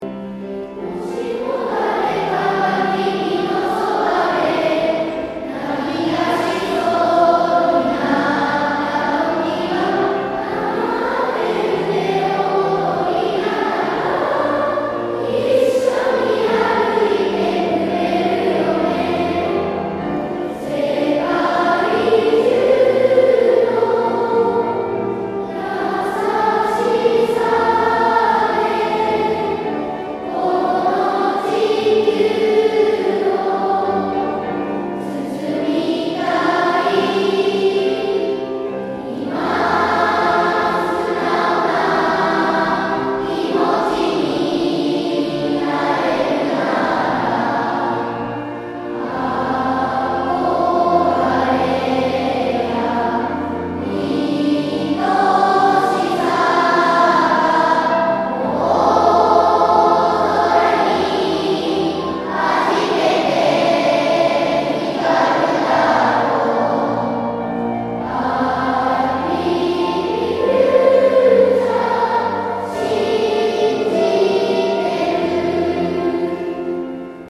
３校時に６年生を送る会を開きました。